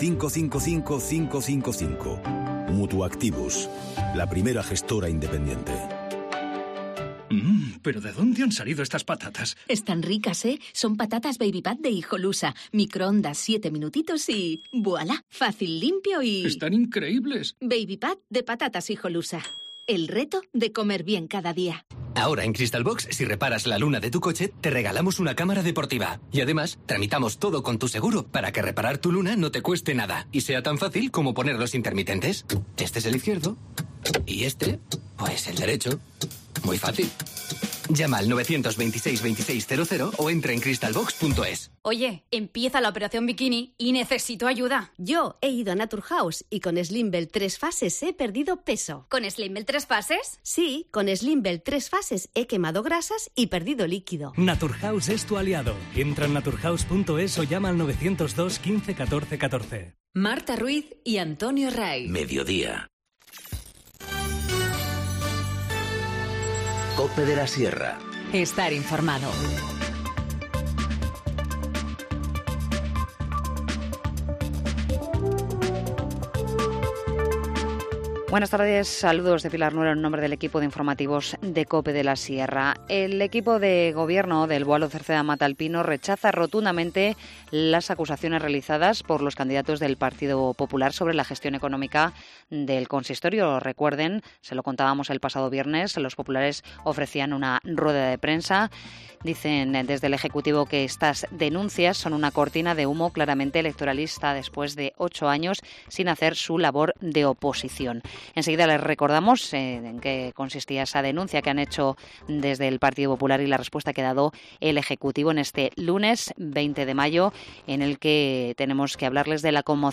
Informativo Mediodía 20 mayo 14:20h